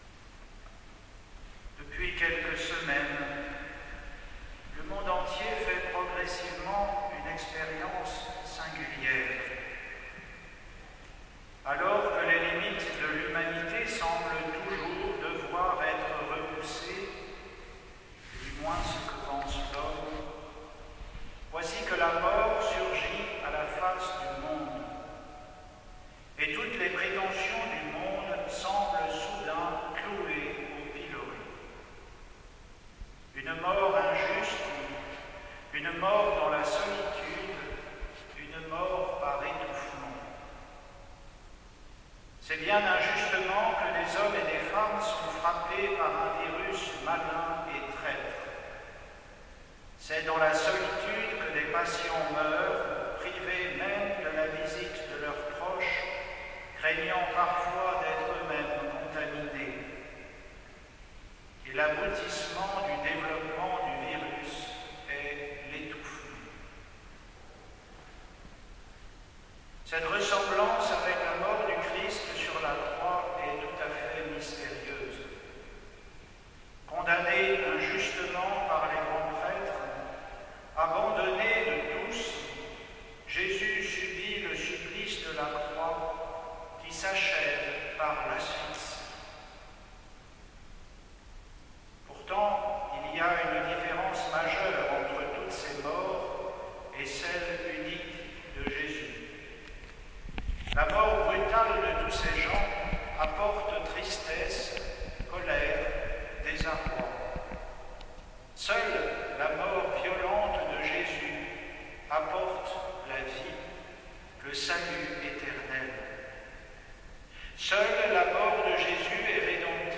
Homélie pour le Vendredi Saint, 10 avril 2020